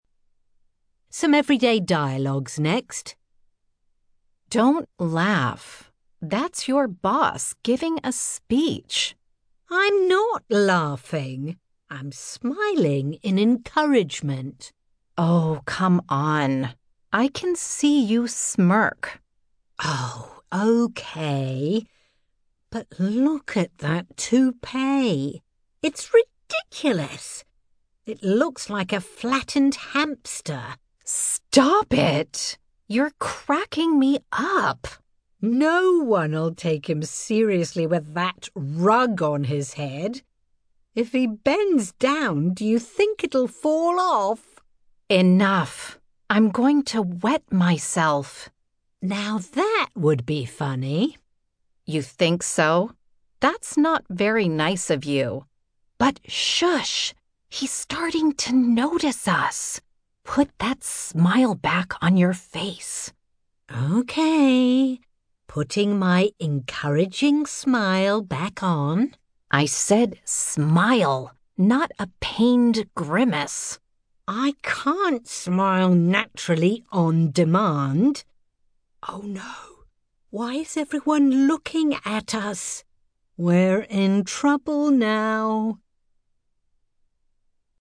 Speaker (American accent)
Speaker (UK accent)